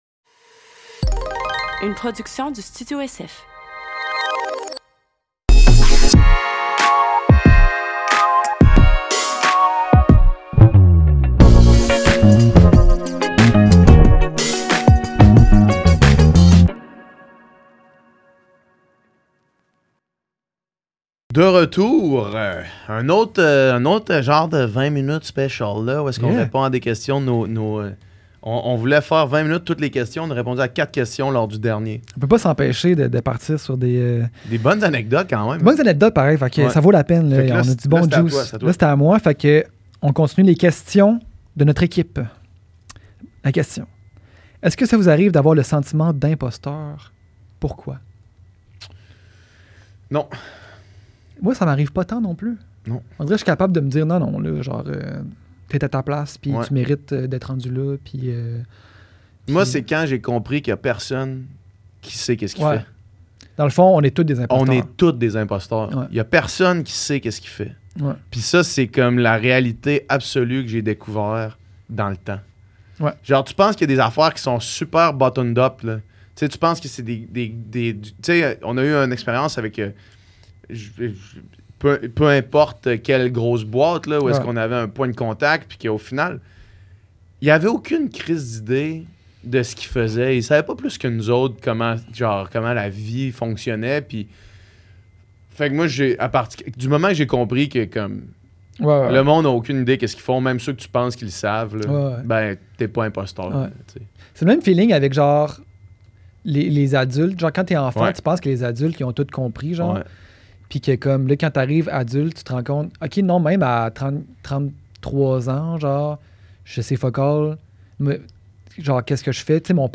Cette semaine sur le podcast, c\'est la suite de notre Q&A au format court avec des questions posées par notre équipe au Studio SF.